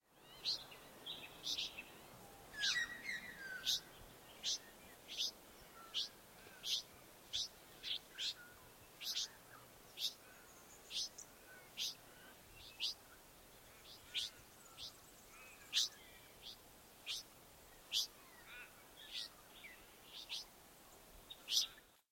They are starting to feed themselves on nectar in a Yellow Gum (Eucalyptus leucoxylon), but are still begging for food and maintain a regular contact call with their parents (listen to audio below), and the yellow gape they had as nestlings is still visible.
red-wattlebird-chicks.mp3